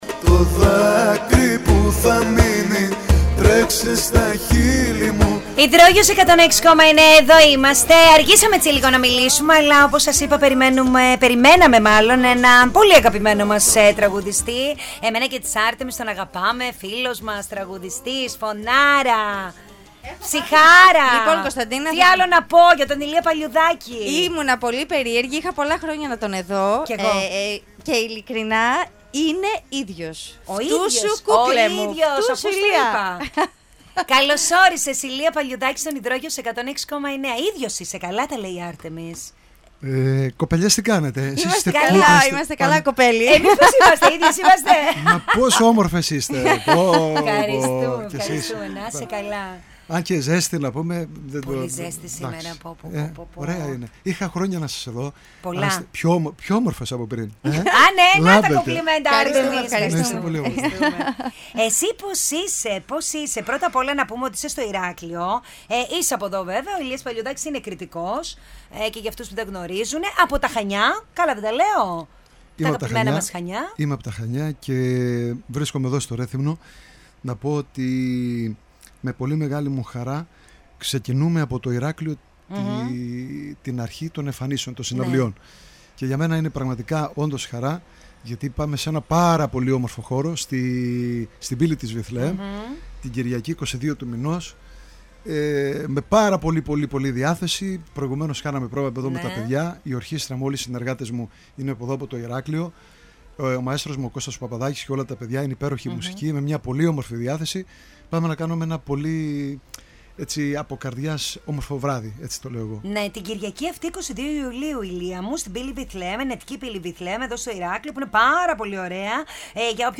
Ακούστε την Συνέντευξη που έδωσε στα κορίτσια μας..